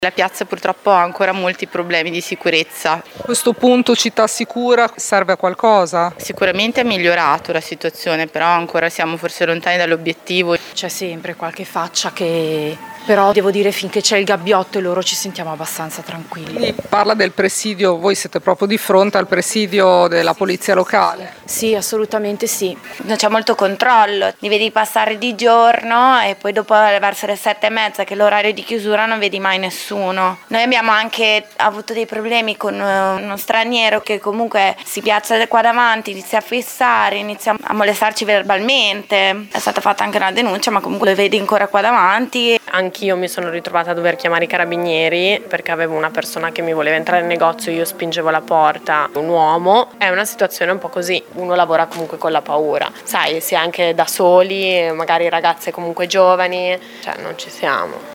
Le interviste:
vox-taglio-montate.mp3